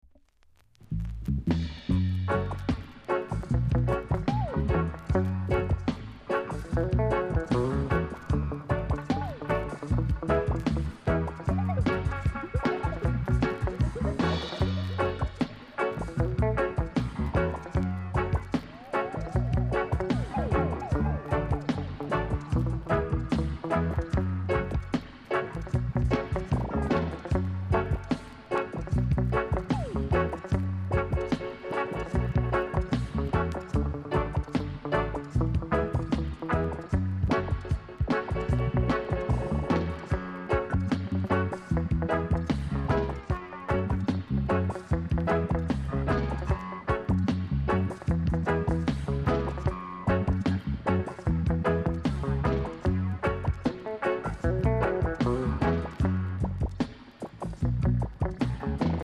コメント KILLER ROOTS!!